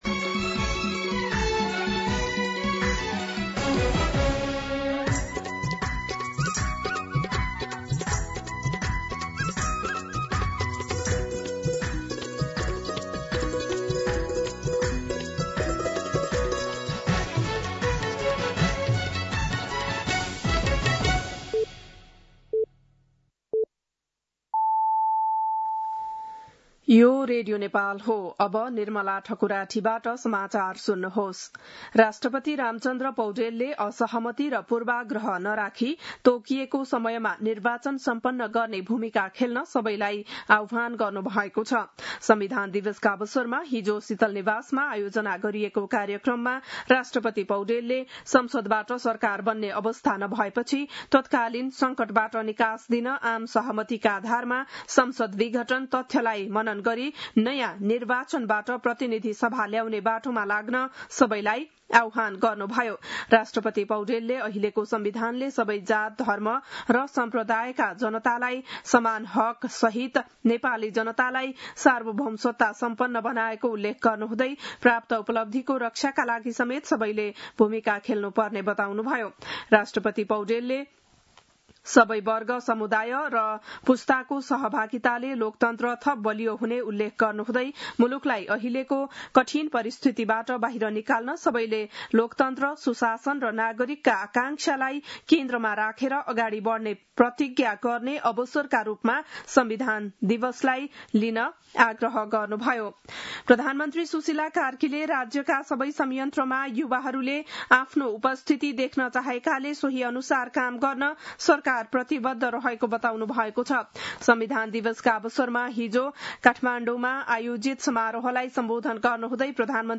बिहान ११ बजेको नेपाली समाचार : ४ असोज , २०८२
11-am-Nepali-News-2.mp3